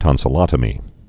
(tŏnsə-lŏtə-mē)